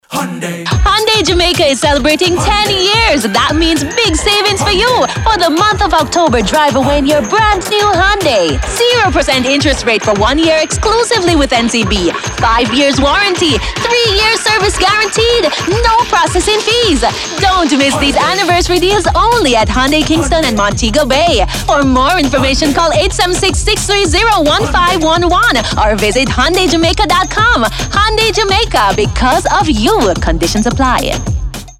This vibrant audio campaign was crafted for an end-of-year initiative to showcase the dealership's exciting upcoming sale promotions on their vehicle inventory.